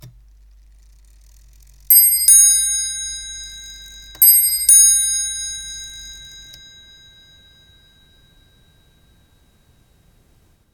Ding-dong-ding-dong
bells ding-dong gears mechanical sound effect free sound royalty free Sound Effects